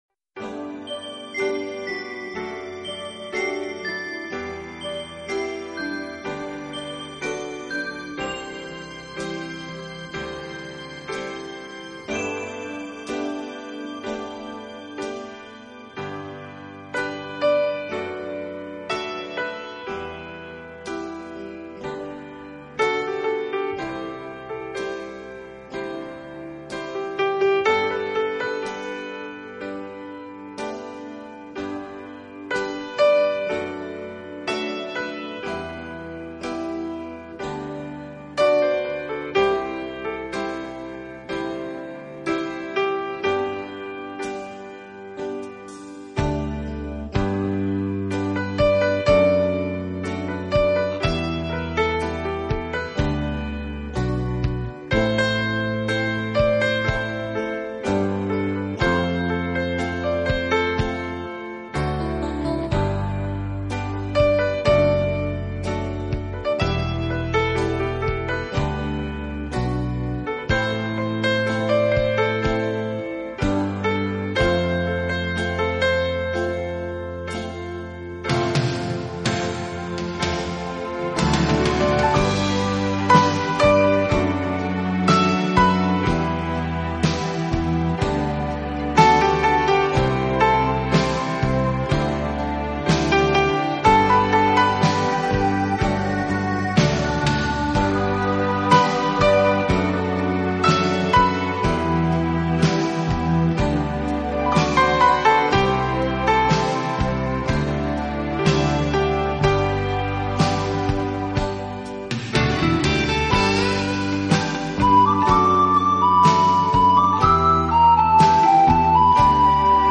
钢琴演奏版，更能烘托出复古情怀，欧美钢琴大师深具质感的演奏功力，弹指
本套CD全部钢琴演奏，